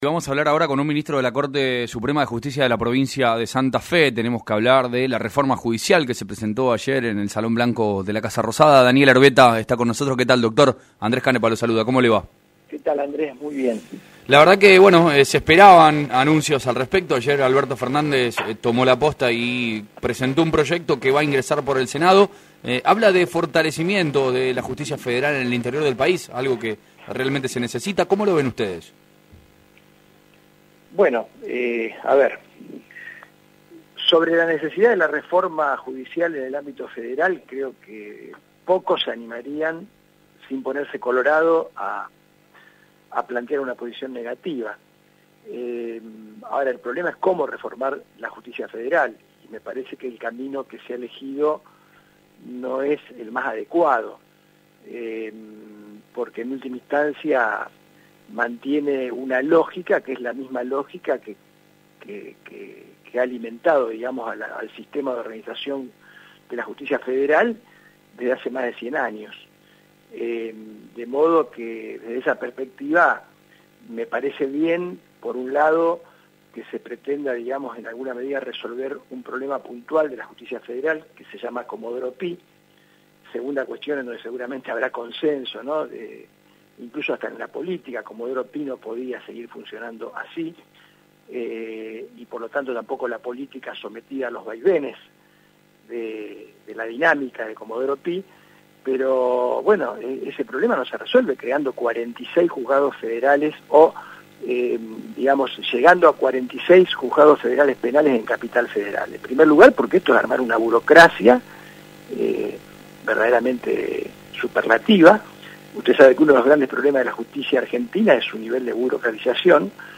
Daniel Erbetta en Radio Mitre Rosario